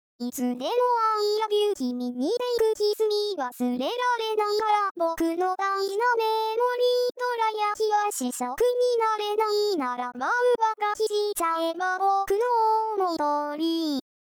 只调了开头几句 因为是泄露的音库所以应该算是非法调教罢（笑） 上传时间